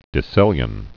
(dĭ-sĭlyən)